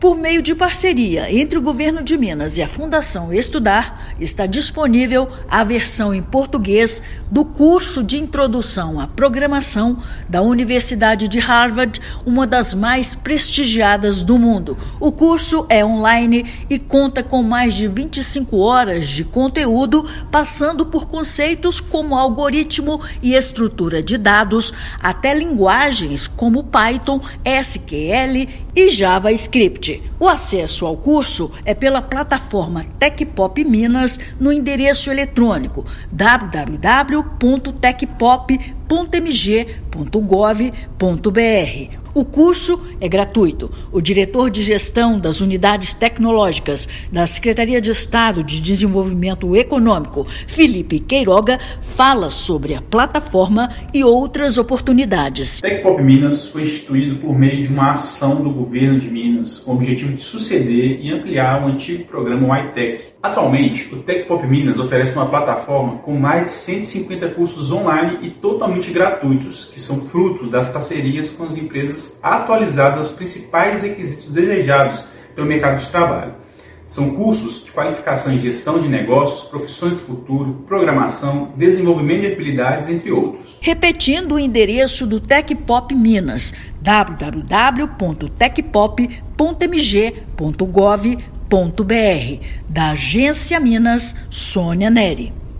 Mineiros podem aprender on-line, sem qualquer custo. Projeto do governo mineiro garante inclusão digital e capacitação profissional Ouça matéria de rádio.